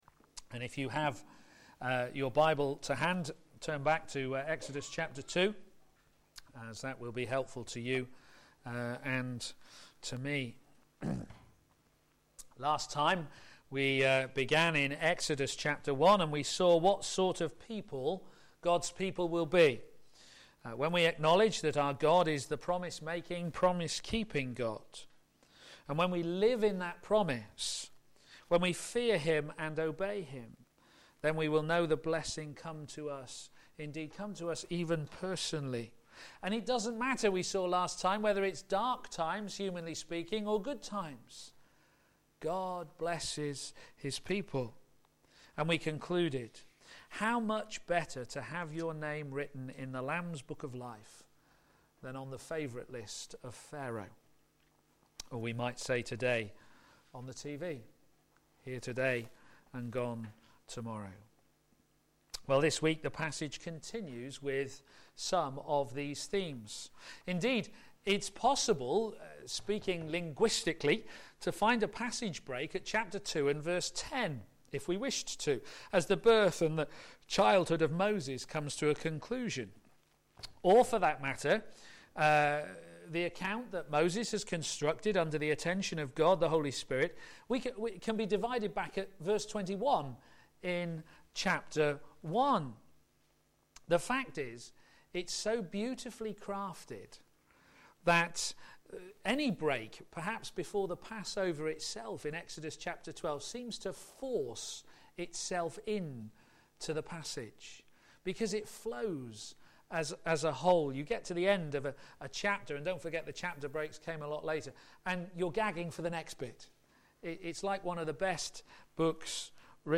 Media Library Media for p.m. Service on Sun 13th Jan 2013 18:30 Speaker
Help is on the way! Sermon